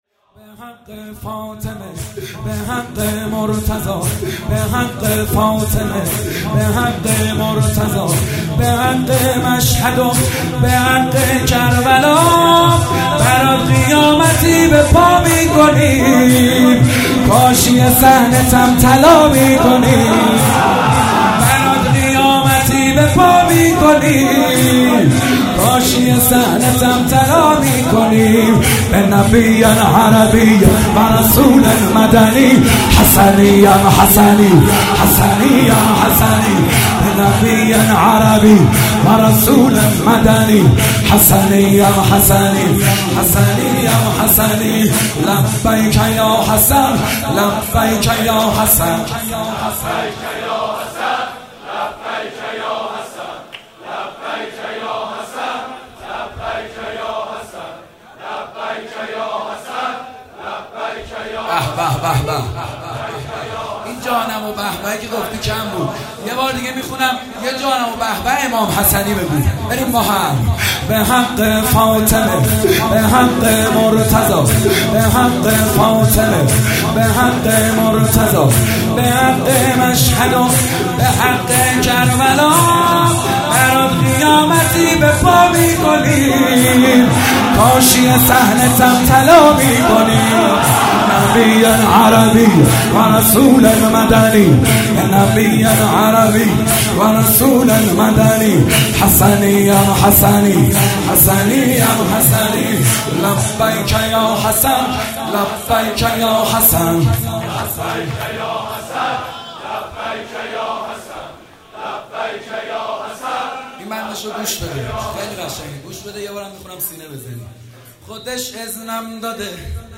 تک جدید